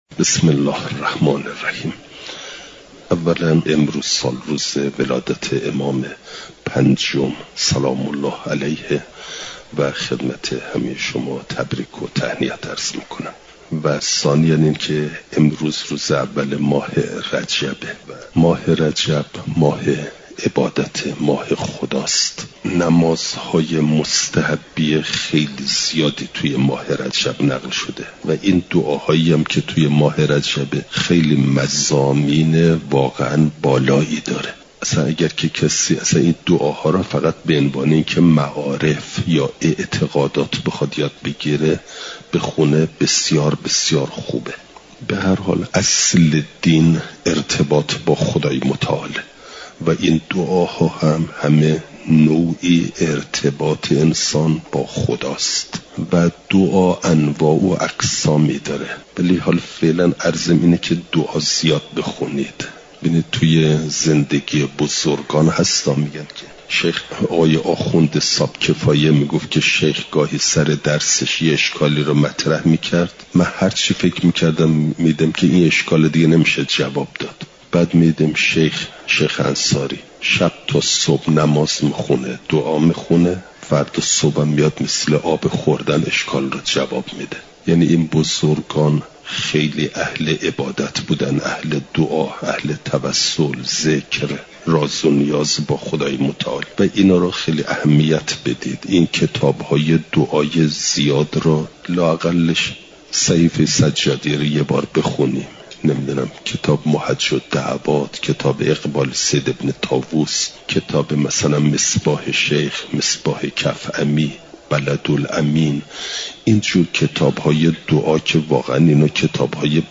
بیانات اخلاقی
دوشنبه ۱ دیماه ۱۴۰۴، حرم مطهر حضرت معصومه سلام ﷲ علیها